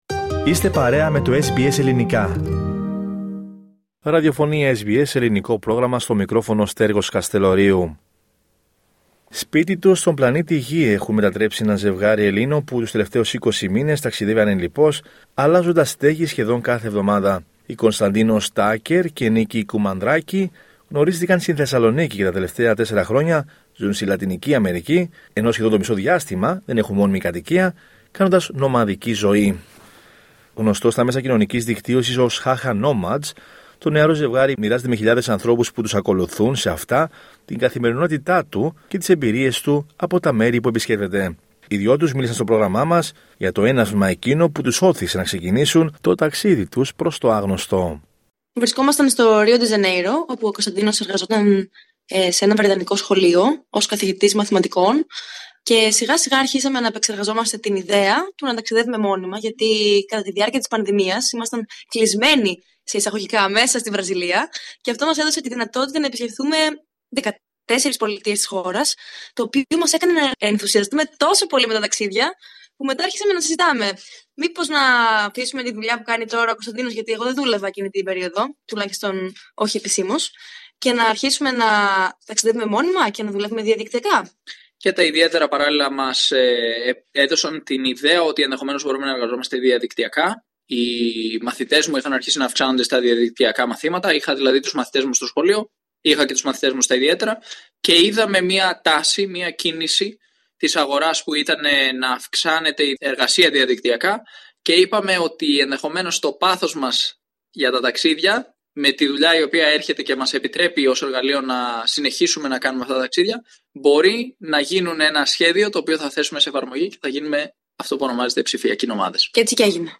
Οι δυο τους μίλησαν στο Ελληνικό Πρόγραμμα της Ραδιοφωνίας SBS, για το έναυσμα εκείνο που τους ώθησε να ξεκινήσουν το ταξίδι τους στο… άγνωστο.